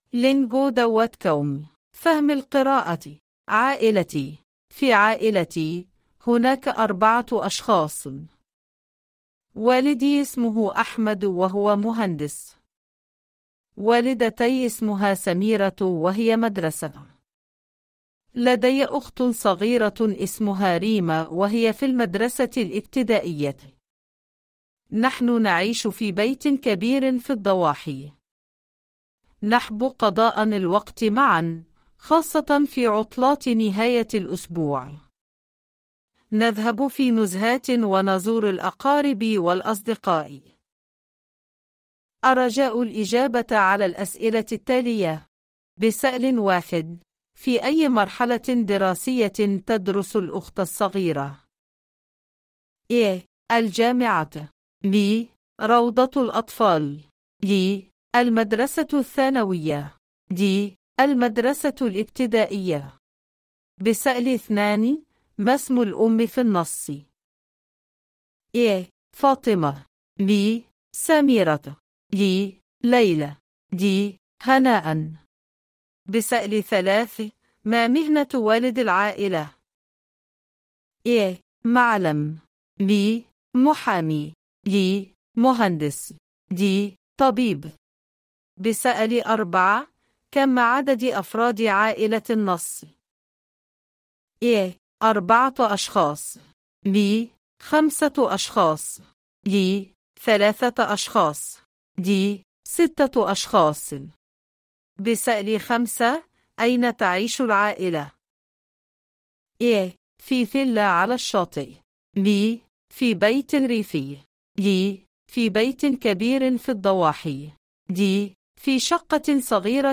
Lectura